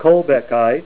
Say KOLBECKITE Help on Synonym: Synonym: Eggonite   Sterrettite